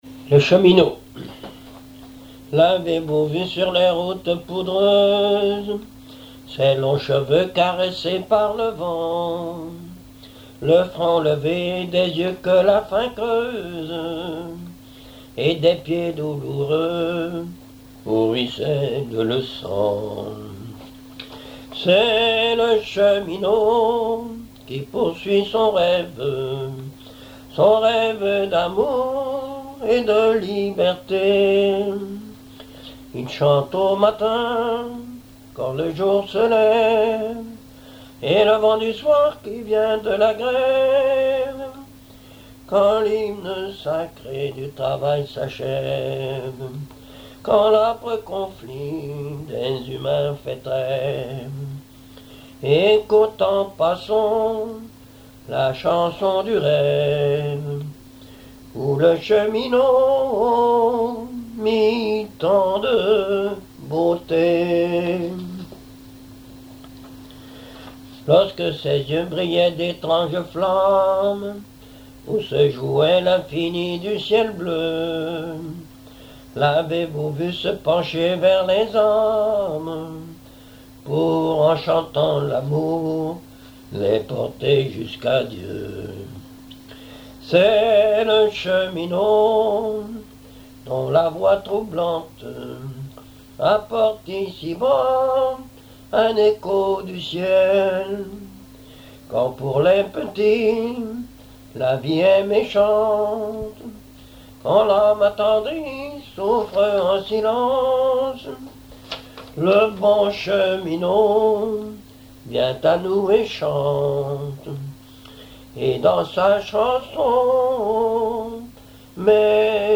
Mémoires et Patrimoines vivants - RaddO est une base de données d'archives iconographiques et sonores.
Genre strophique
chansons populaires et histoires drôles
Catégorie Pièce musicale inédite